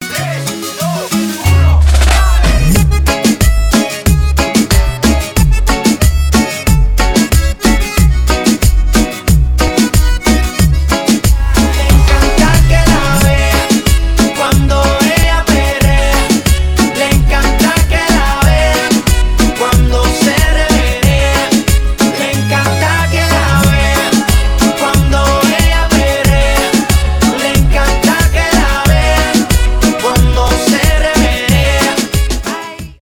реггетон
поп
веселые , латиноамериканские